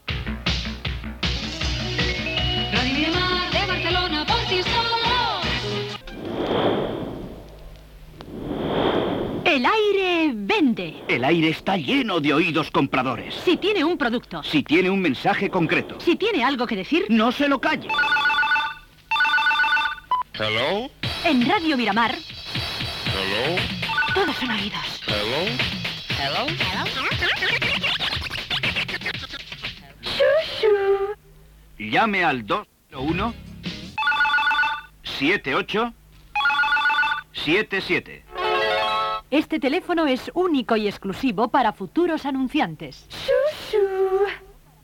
Indicatiu de l'emissora i publictat "El aire vende" (telèfon per anuncicar-se a Radio Miramar)